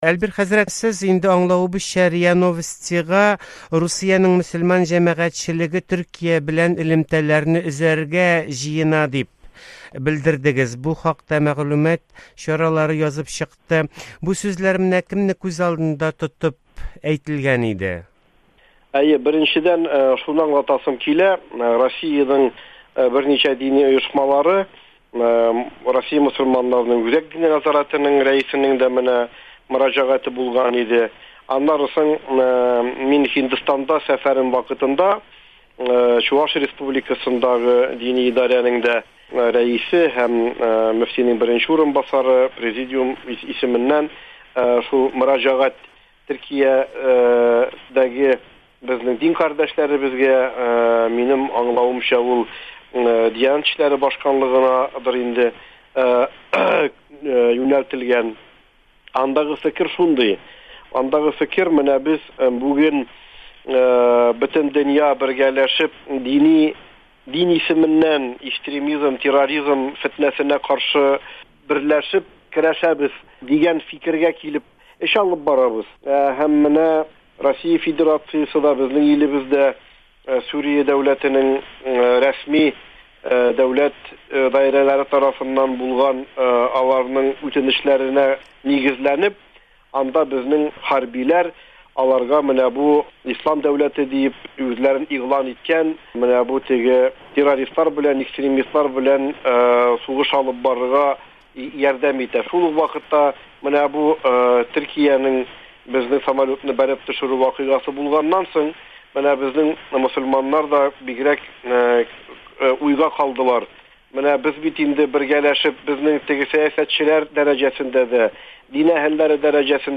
Әлбир Крганов белән әңгәмә